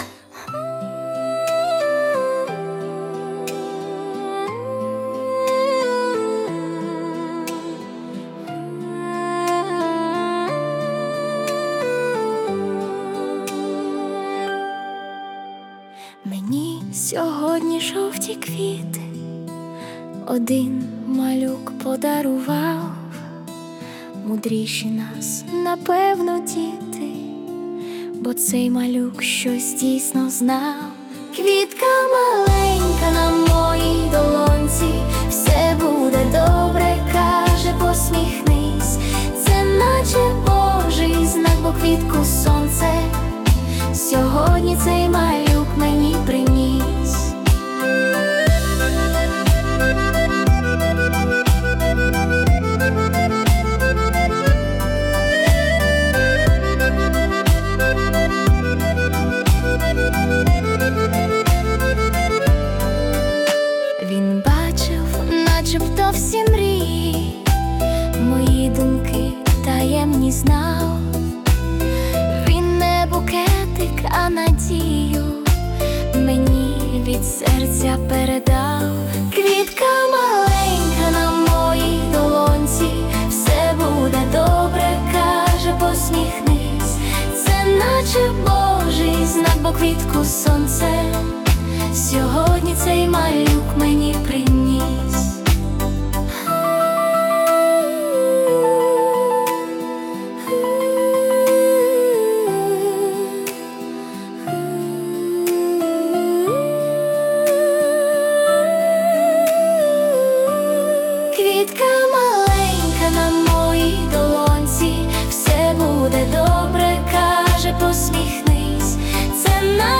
І ось вийшла така проста легка мелодія, як і дарунок дитини.